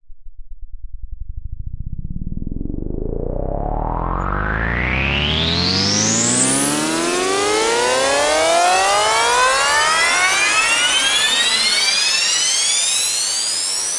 EDM立管 " 立管投球01
描述：在收割者中使用Massive制作的立管。八条长。
标签： 合成器 电子乐 打击乐 EDM 精神恍惚 舞蹈
声道立体声